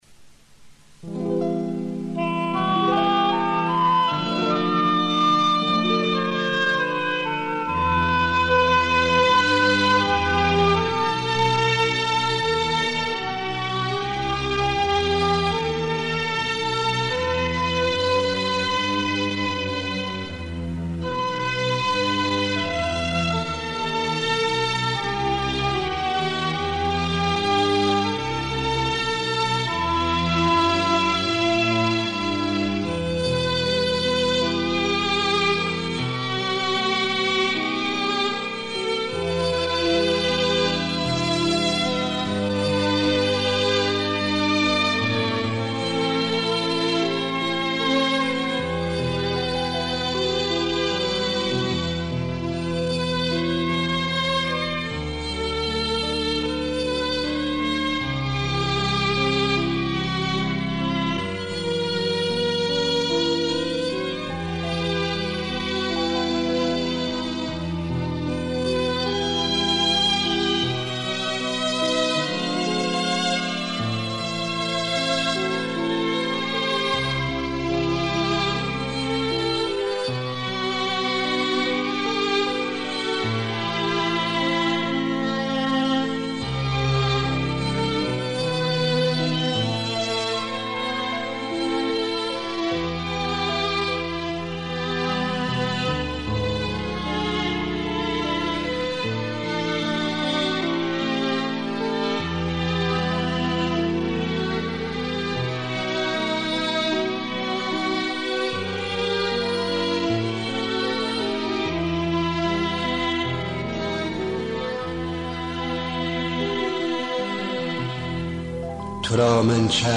پخش آنلاین دانلود نسخه صوتی دکلمه دانلود